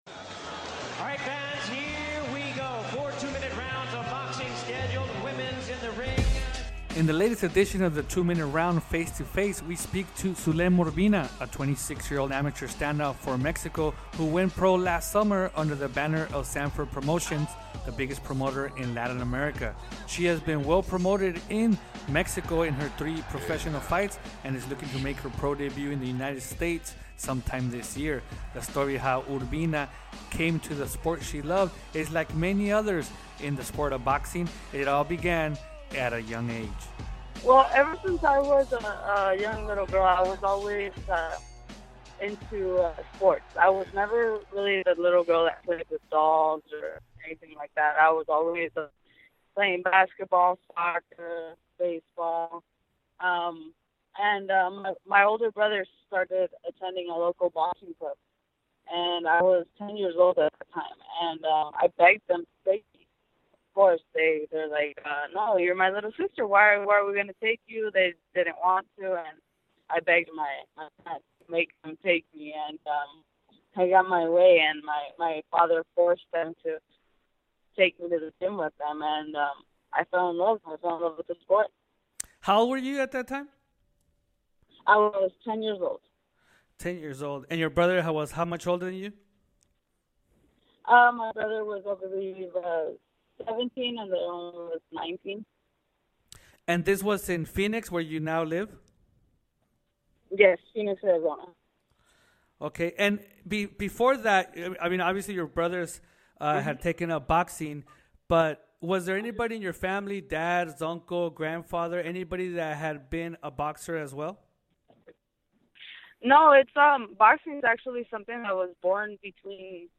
In this candid interview